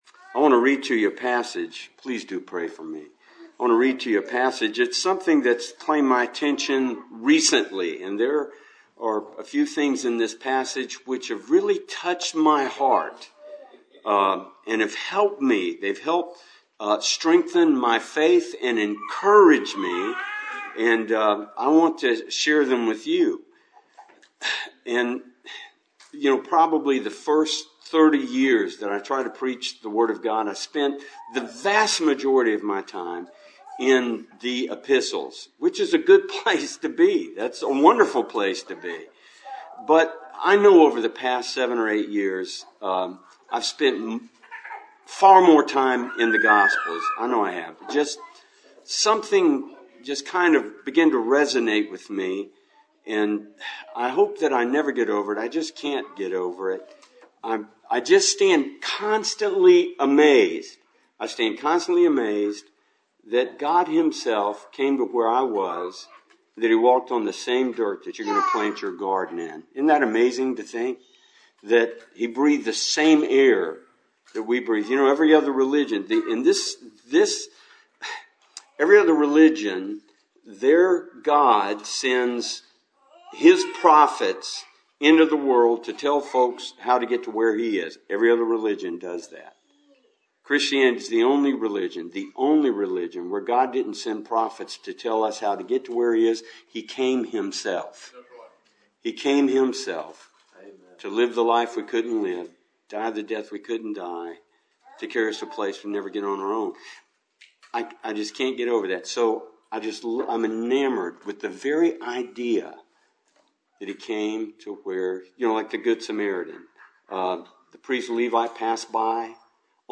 John 2 Service Type: Cool Springs PBC Sunday Evening « Heaven Psalms 71-Part 1 »